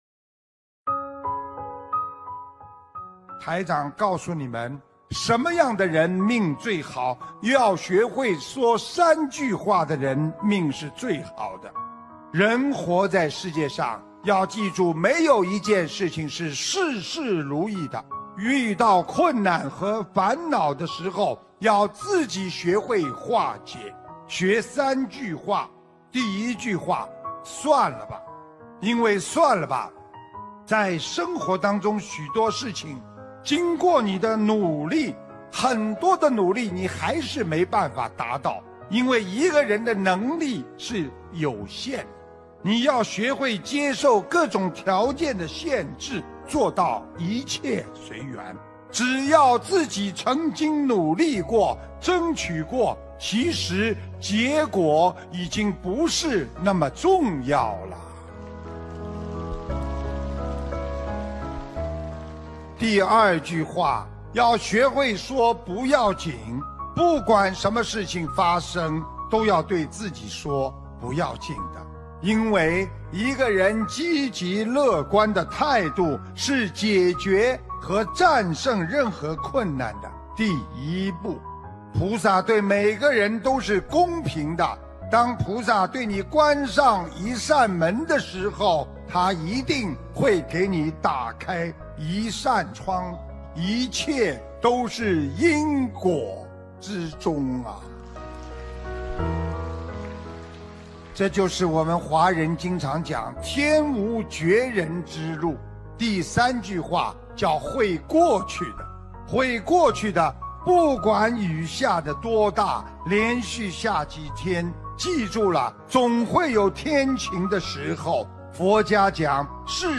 2018年10月14日 - 法会节选 心灵净土